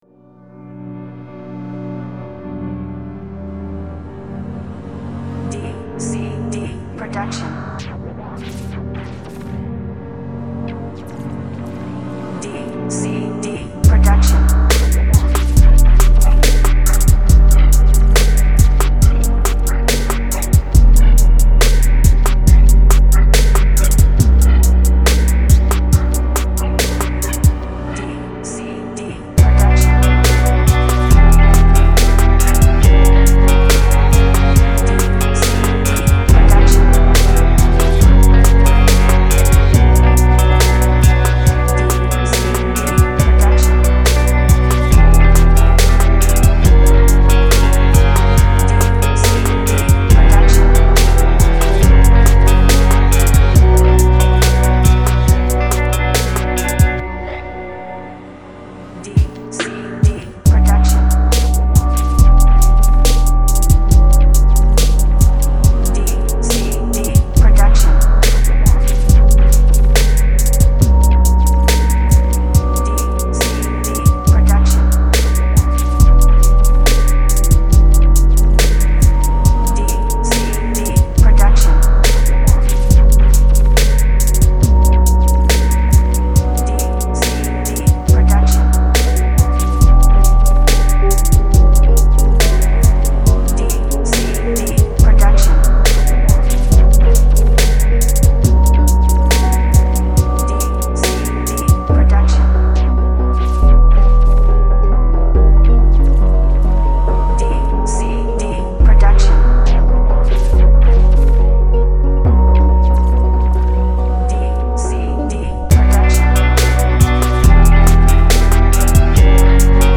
Жанр: Hip-hop, jazzy beats